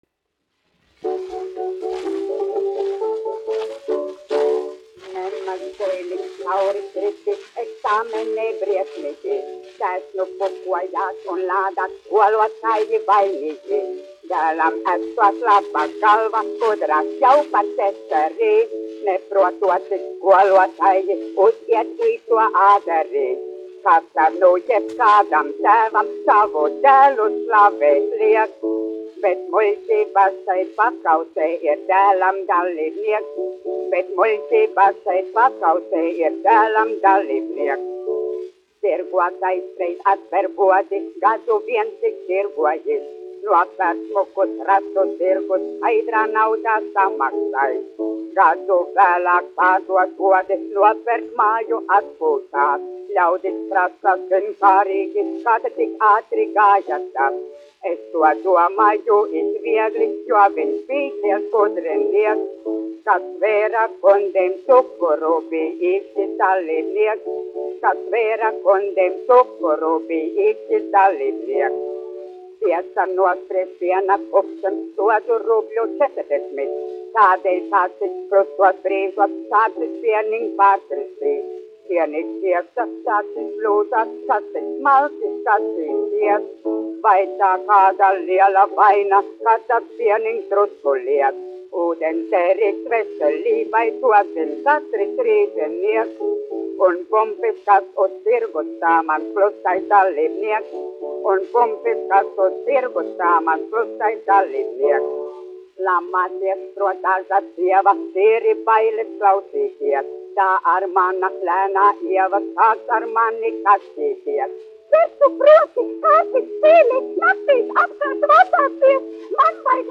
1 skpl. : analogs, 78 apgr/min, mono ; 25 cm
Populārā mūzika
Humoristiskās dziesmas
Dzied Rīgas Latviešu teātra komiķis
Skaņuplate